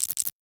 NOTIFICATION_Subtle_01_mono.wav